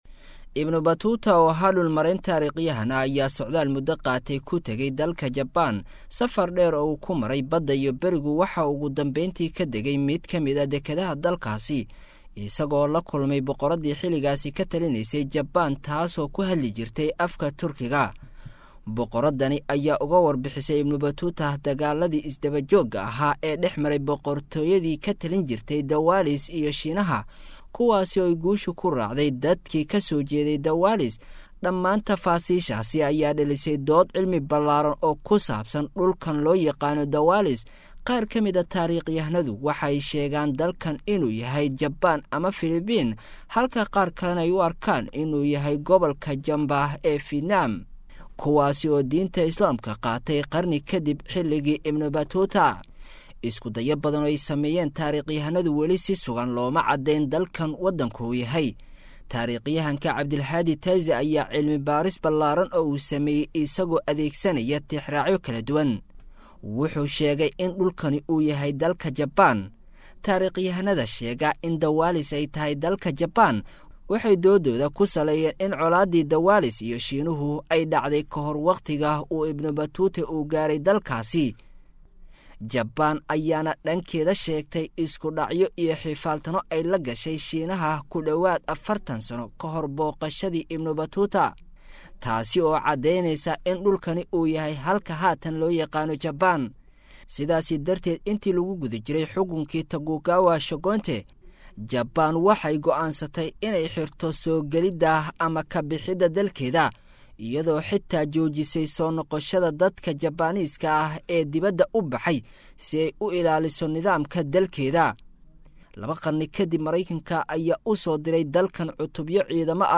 Siduu Islaamku ku Gaaray Japan Muxuuse Noloshooda Ka Bedelay Markii uu Dhex Faafay?[WARBIXIN] - Warbaahinta Al-Furqaan